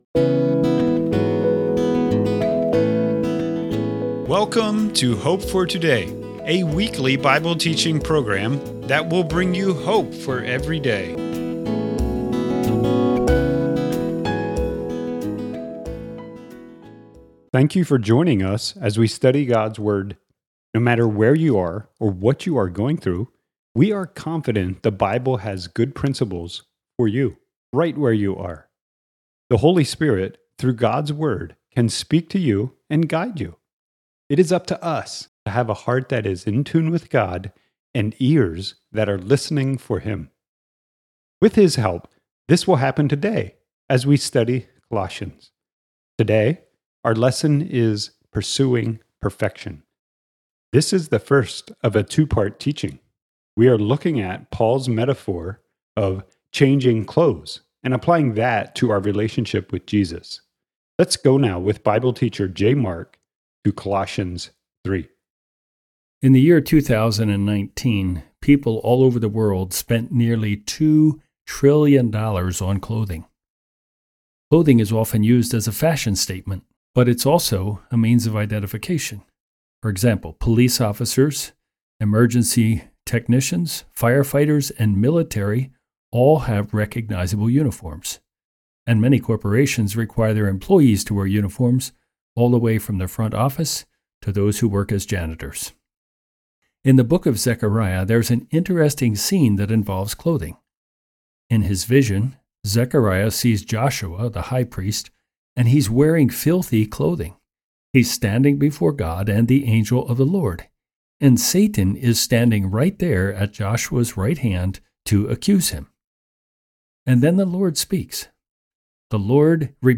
This is the first of a two-part teaching. We are looking at Paul’s metaphor of “changing clothes” and applying that to our relationship with Jesus.